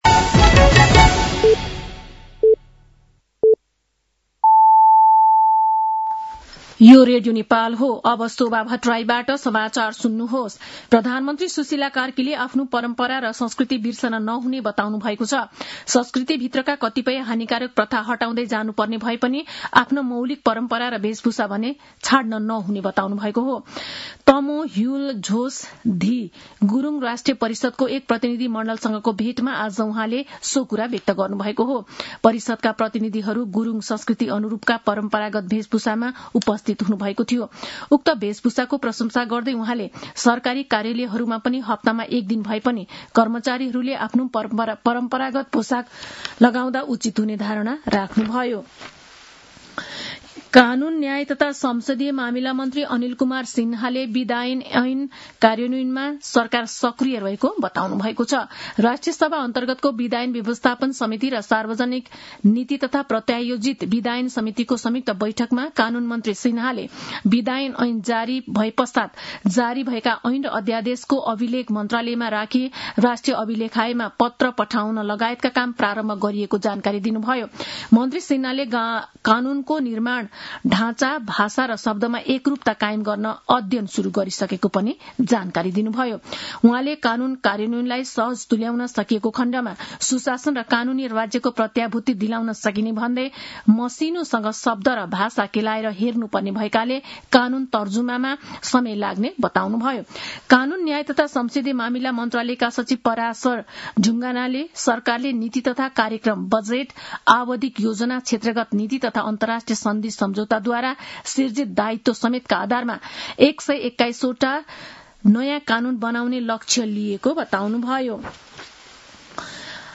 साँझ ५ बजेको नेपाली समाचार : ६ पुष , २०८२
5-pm-nepali-news-9-06.mp3